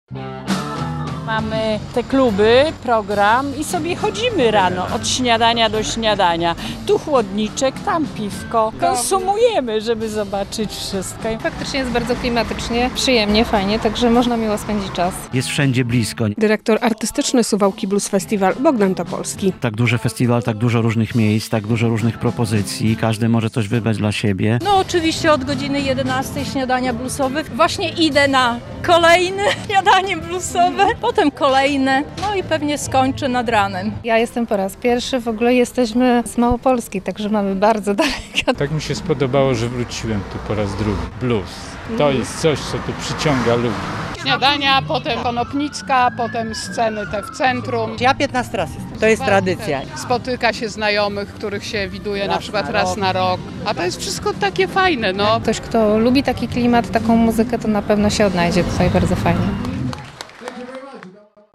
Drugi dzień Suwałki Blues Festival - tradycyjnie rozpoczął się od śniadań bluesowych - relacja